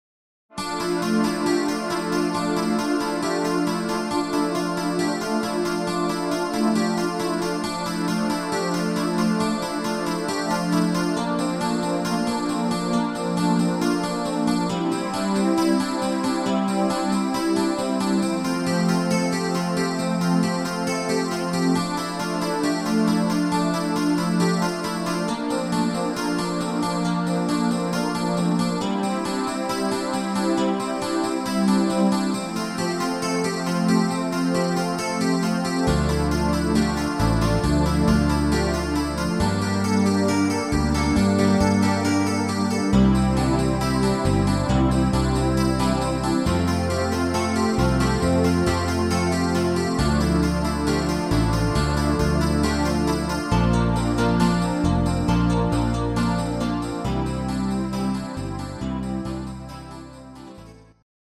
instr. Panflöte